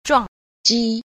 1. 撞擊 – zhuàngjī – chàng kích (va chạm, đánh, đập)